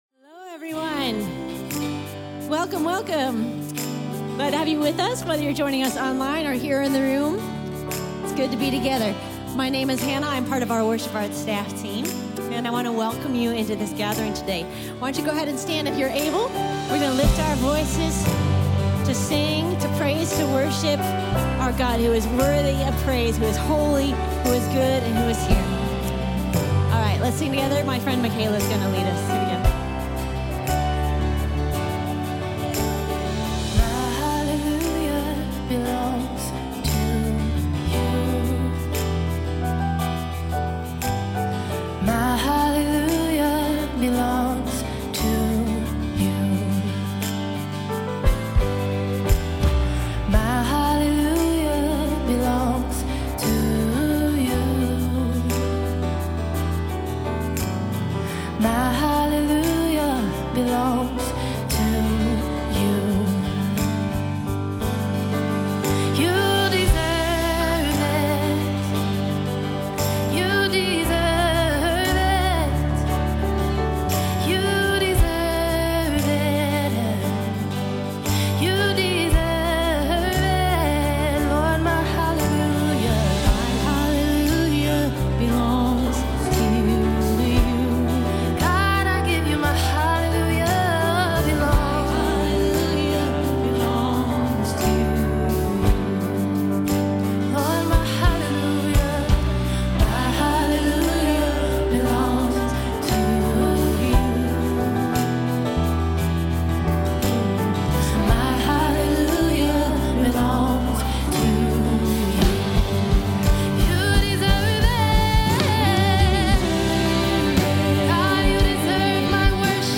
A message from the series "Once Upon a Future."